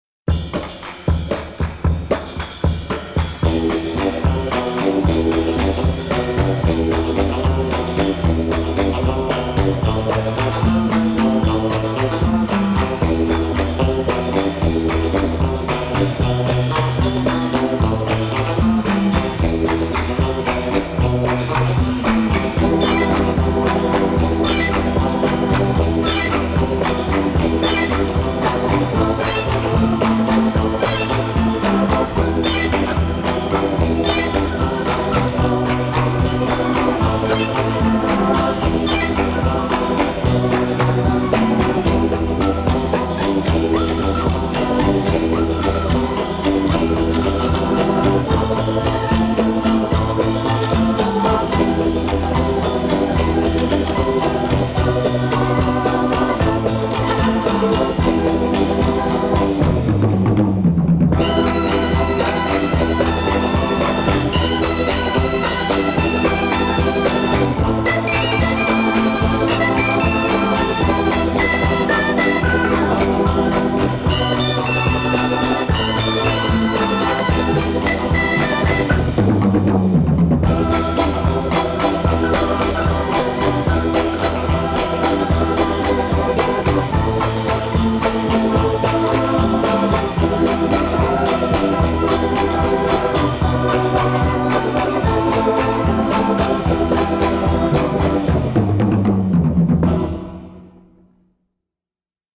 Обожаю surf-music.